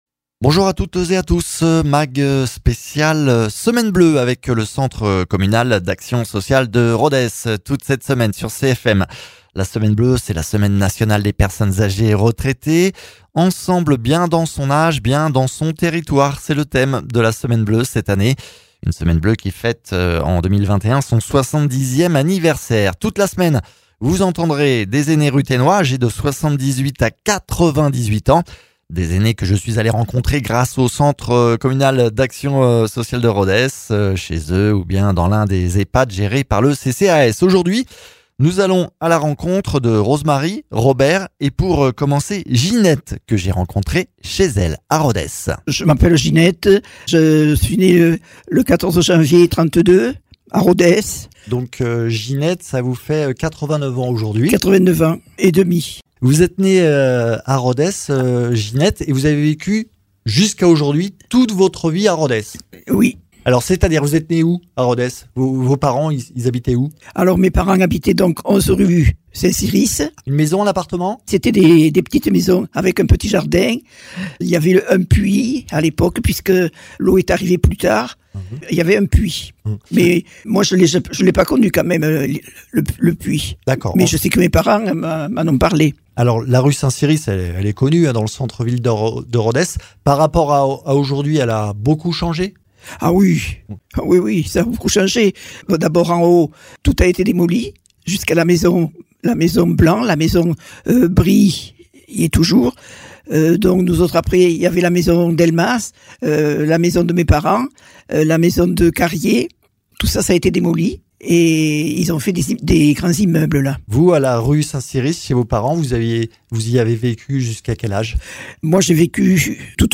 seniors tuthénois.